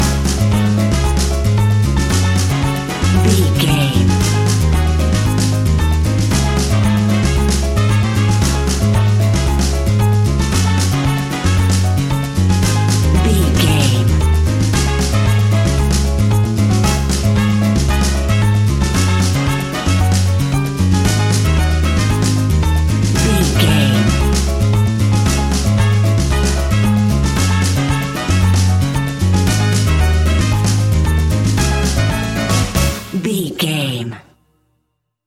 An exotic and colorful piece of Espanic and Latin music.
Aeolian/Minor
C#
instrumentals
maracas
percussion spanish guitar